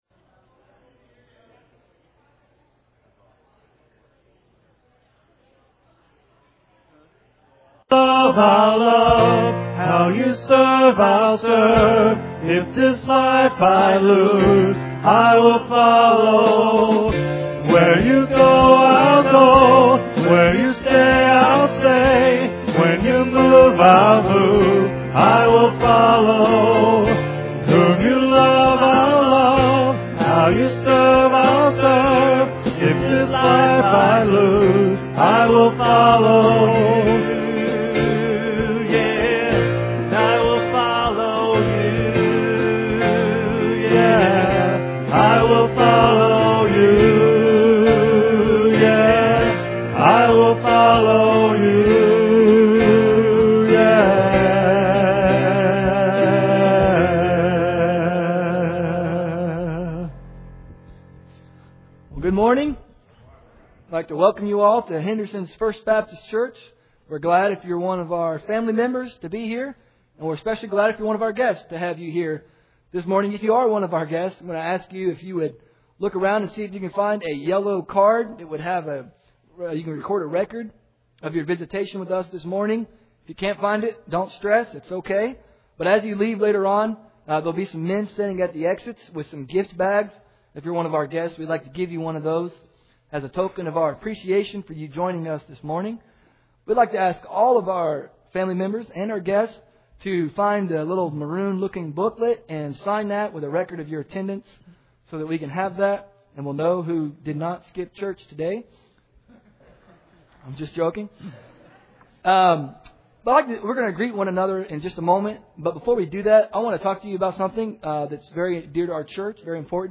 Henderson KY Sermon Introduction Please open your Bibles and turn to Luke chapter nine.